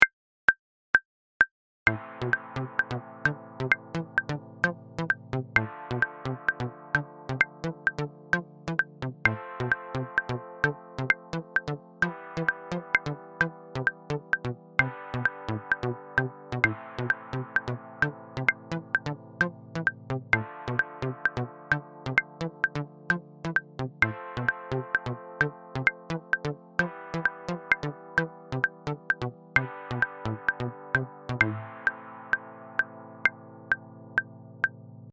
Dotted 8th note groove displacement PDF and mp3s.